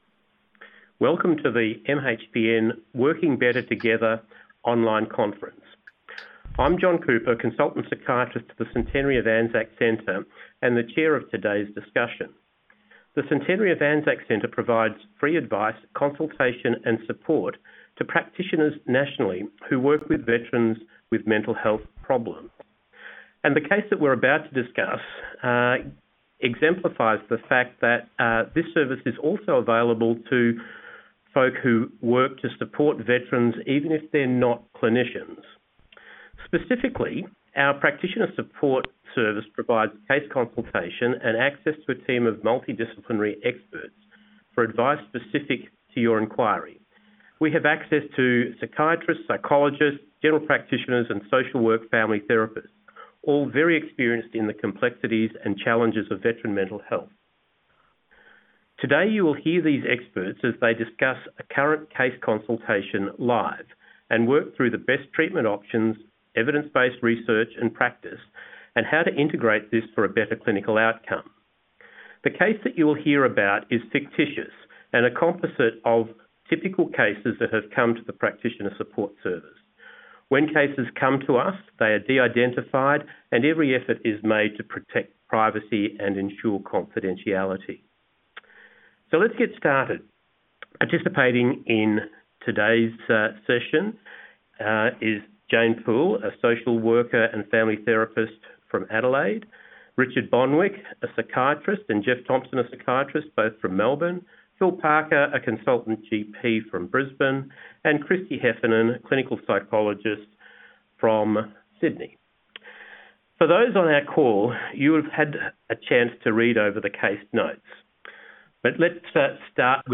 Listen in on this discussion between interdisciplinary experts for a about helping a welfare worker to support a client.